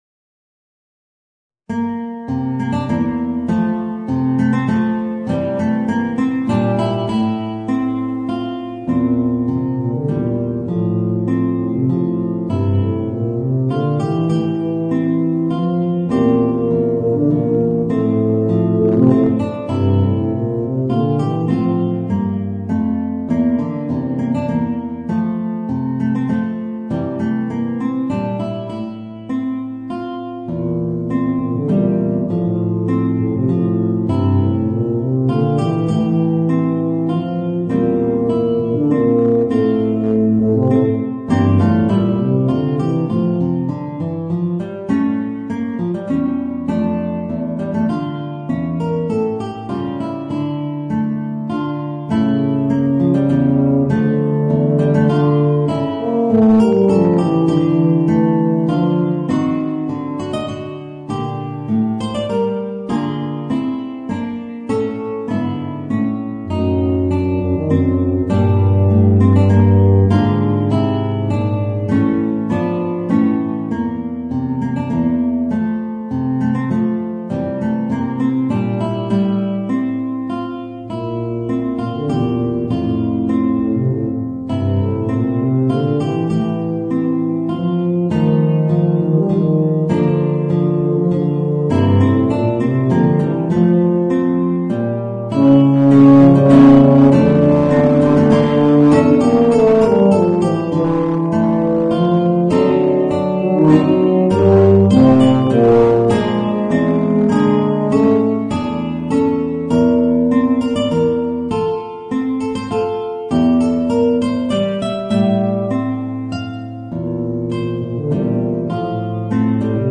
Voicing: Guitar and Eb Bass